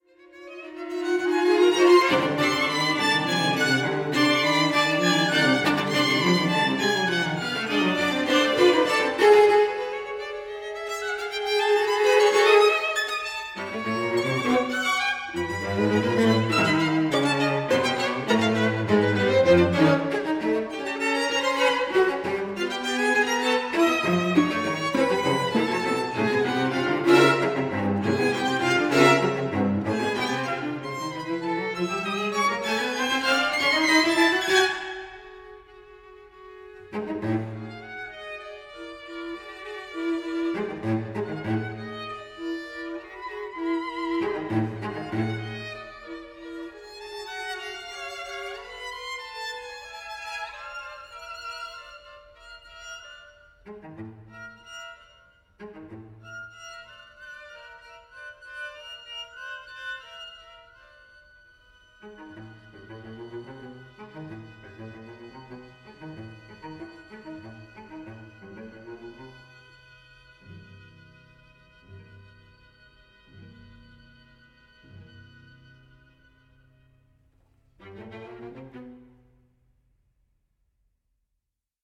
and the jagged sections never become dogged.
CHAMBER MUSIC